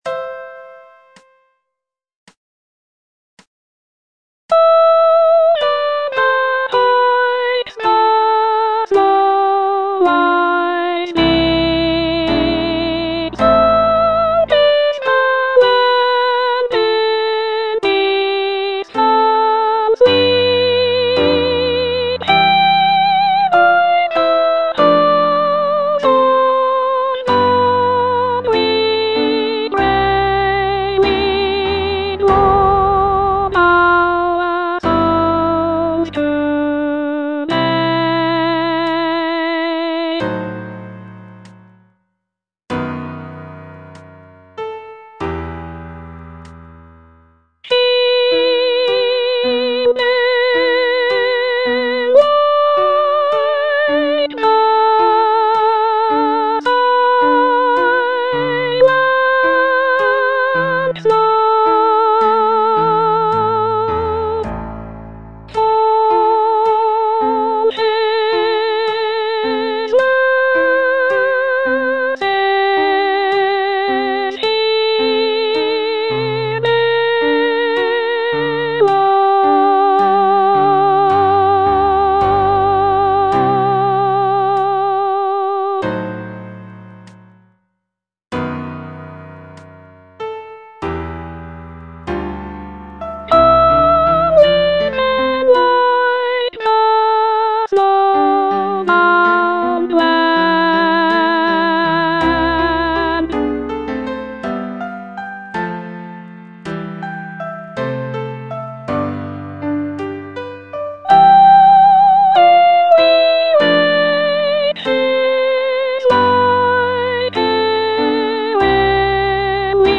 E. ELGAR - FROM THE BAVARIAN HIGHLANDS Aspiration - Soprano (Voice with metronome) Ads stop: auto-stop